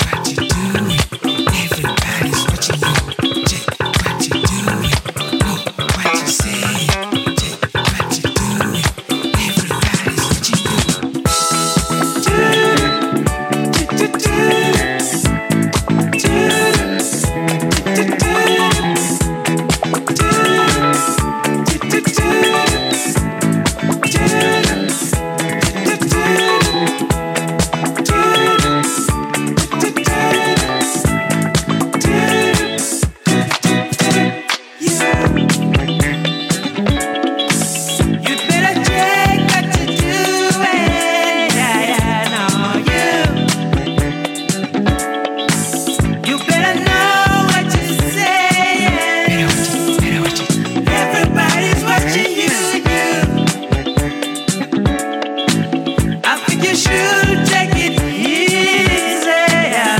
Slick funk grooves powered along with a dose of slap bass.